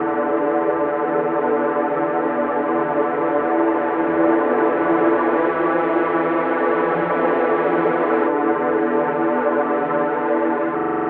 Rameo Strings.wav